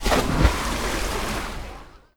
music are now 16bits mono instead of stereo
placed.wav